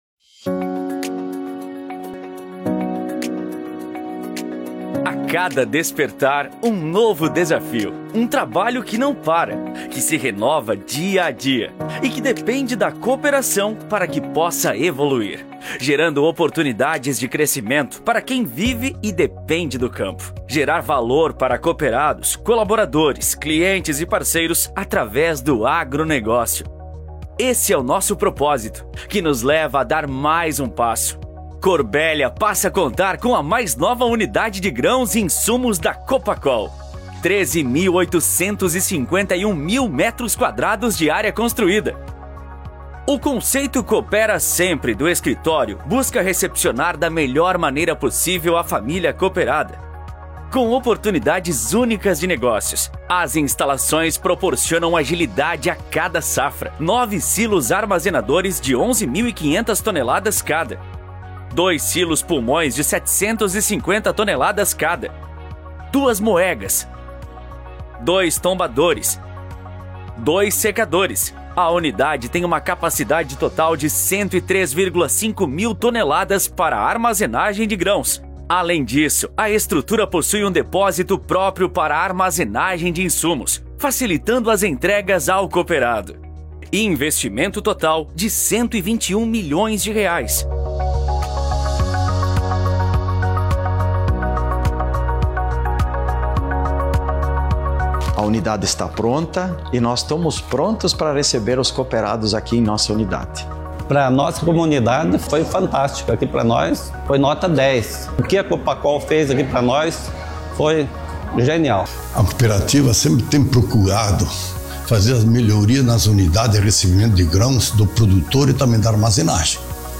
INSTITUCIONAL FELIZ: